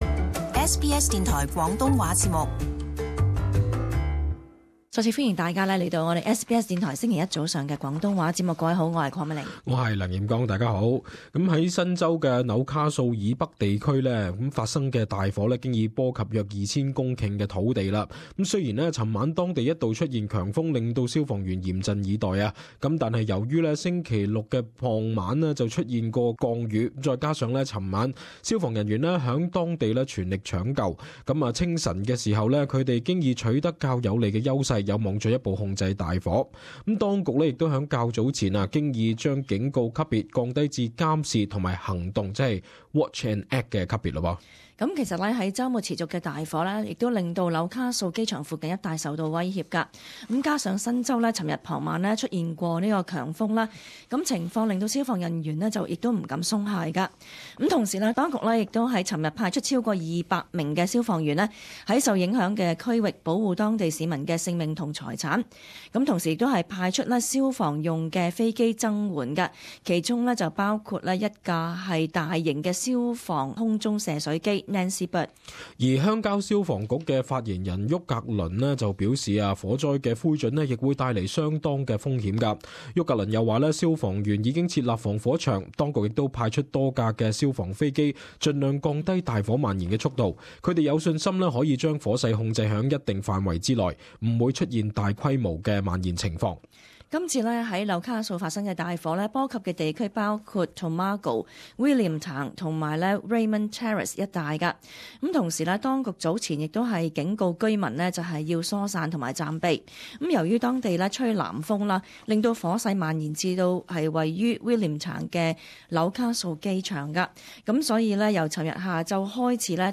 SBS Cantonese